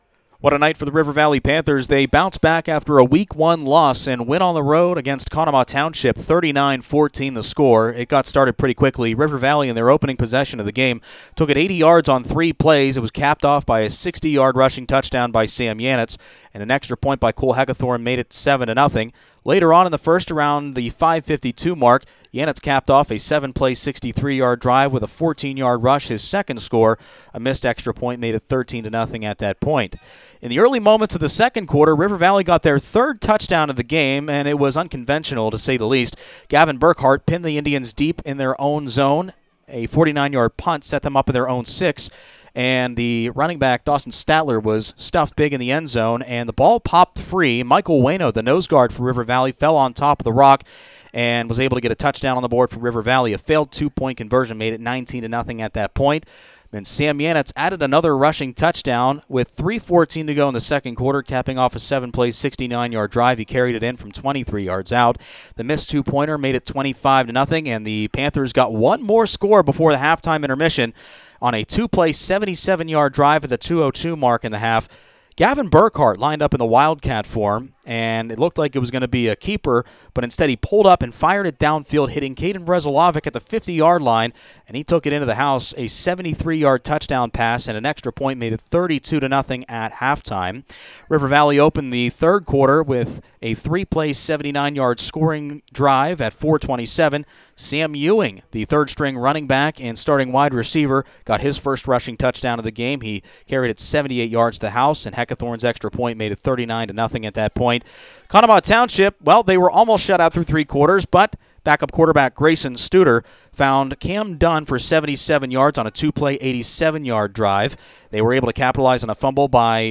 rv-ct-coaches-corner-recap-better-fri-2133.mp3